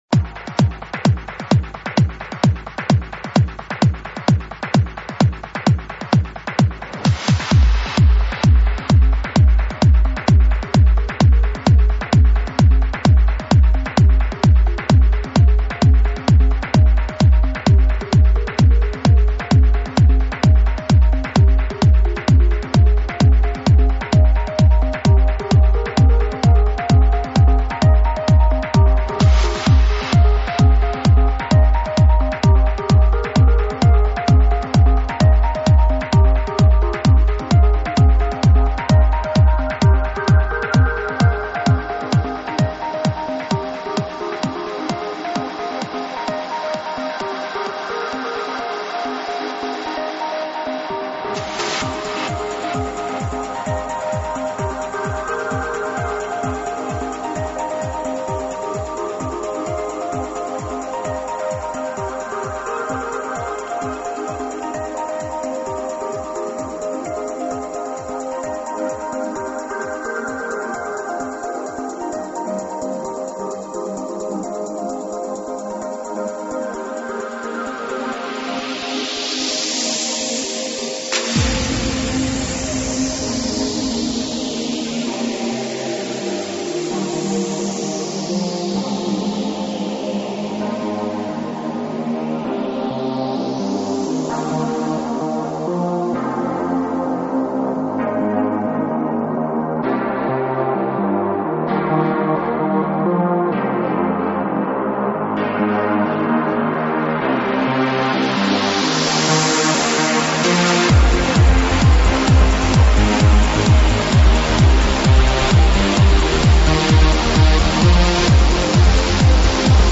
транс сборник